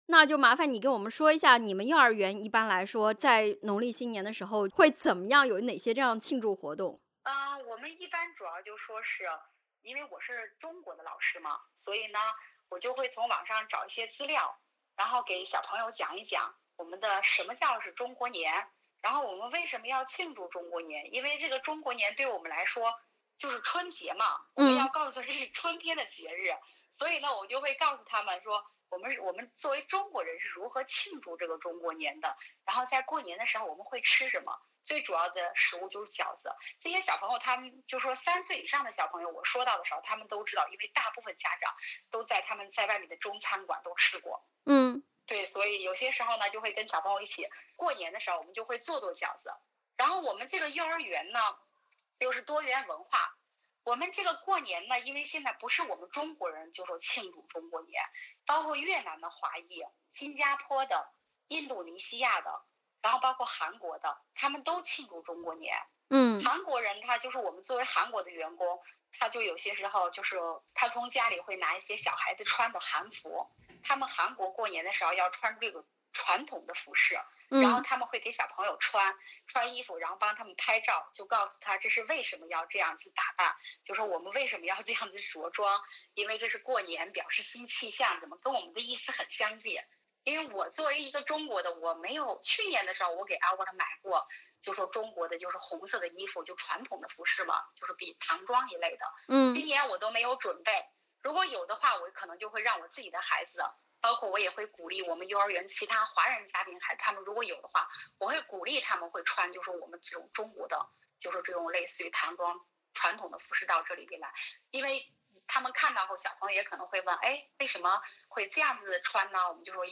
（点击封面图片收听完整采访）